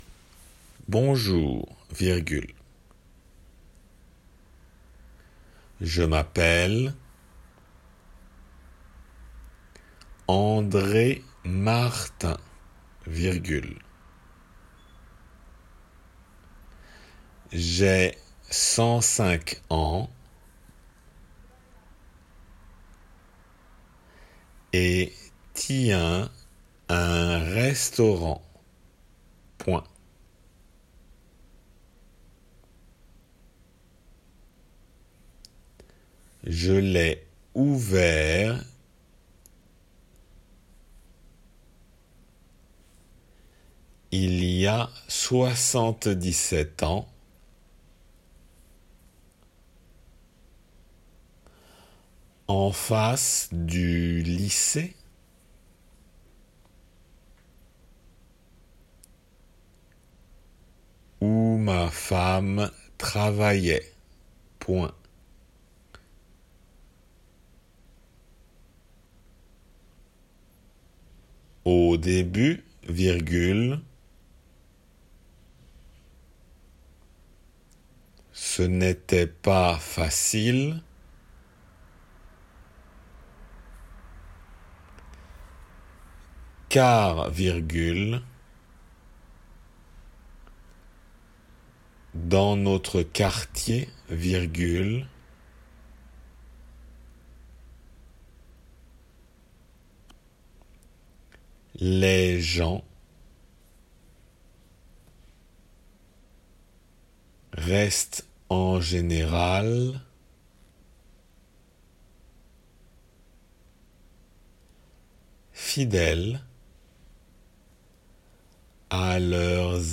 デイクテの速さで